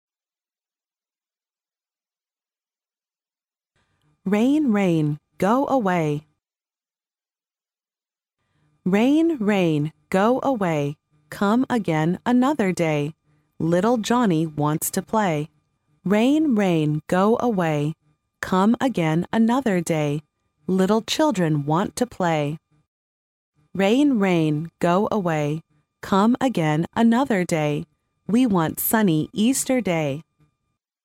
幼儿英语童谣朗读 第17期:雨水 快离开! 听力文件下载—在线英语听力室